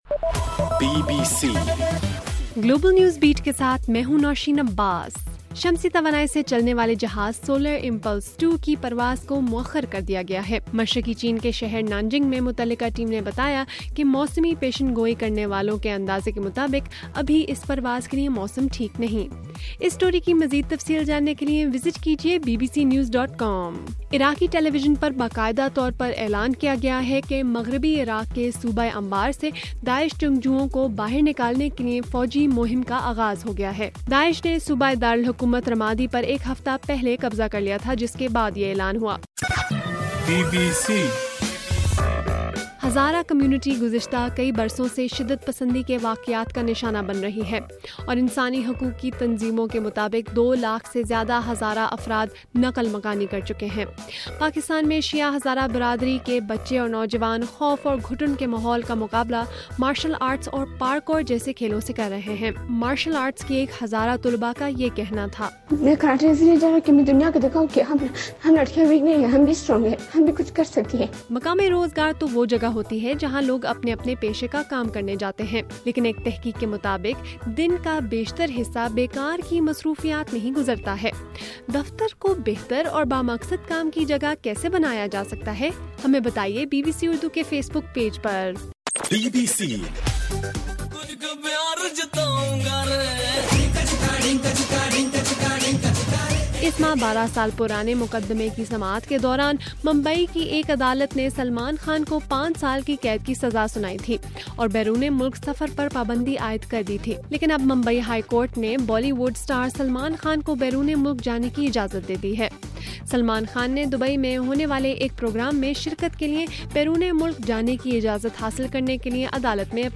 مئی 26: رات 11 بجے کا گلوبل نیوز بیٹ بُلیٹن